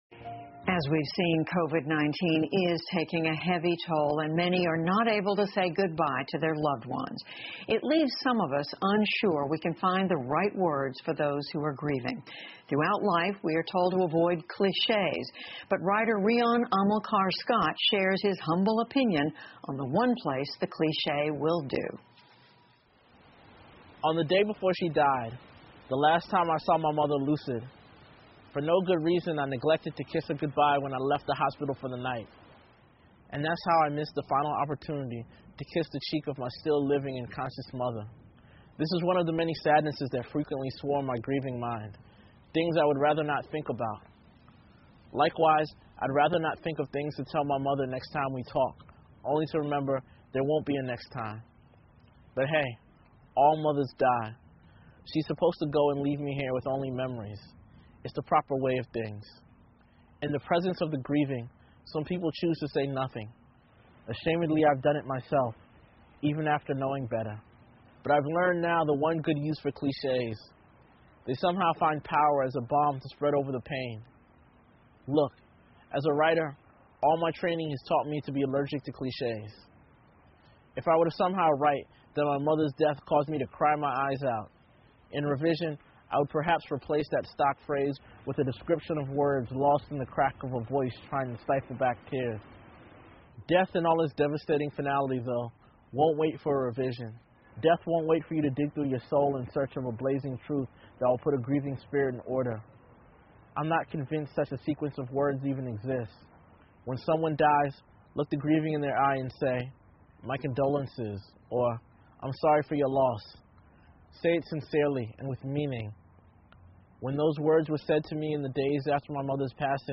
在线英语听力室PBS高端访谈:陈词滥调真的没用吗?的听力文件下载,本节目提供PBS高端访谈教育系列相关资料,内容包括访谈音频和文本字幕。